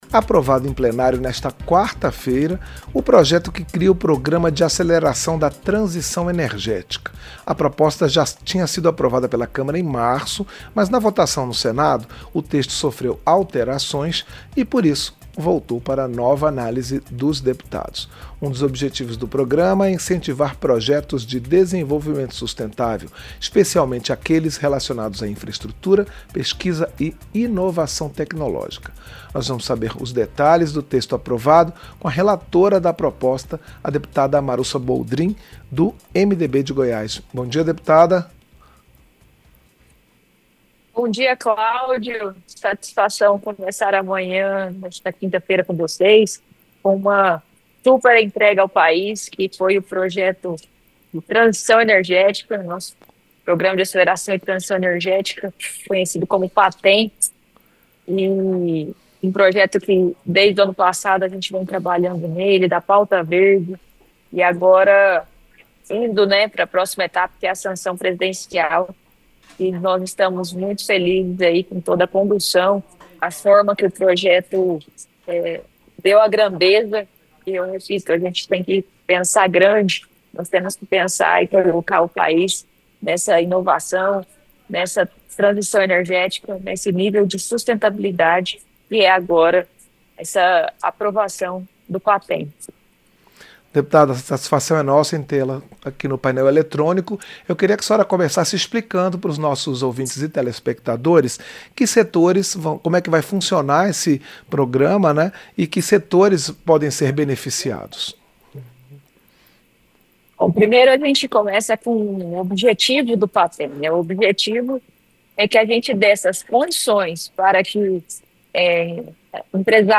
Entrevista - Dep. Marussa Boldrin (MDB-GO)